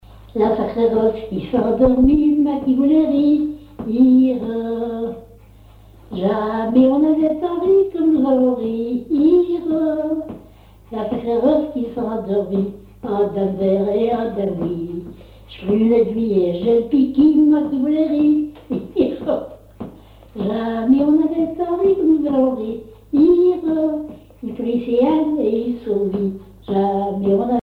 Localisation Cancale (Plus d'informations sur Wikipedia)
Genre laisse
Catégorie Pièce musicale inédite